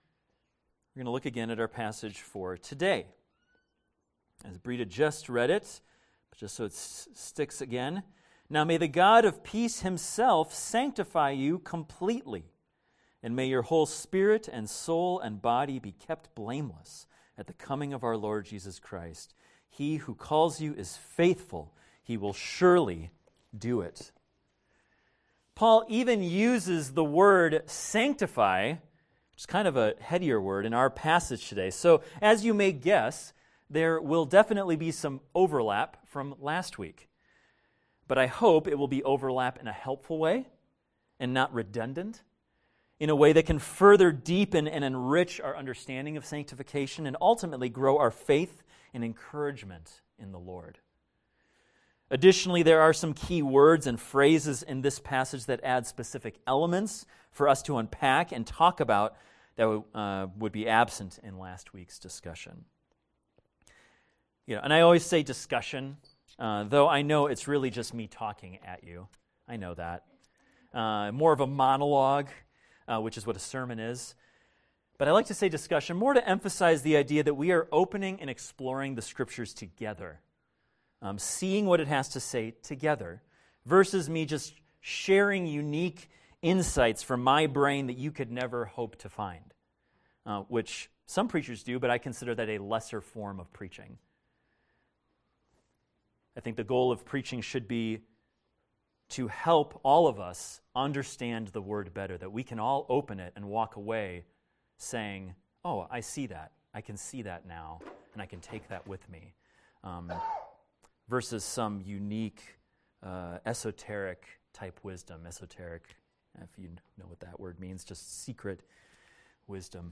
This is a recording of a sermon titled, "Peaceful Perfection."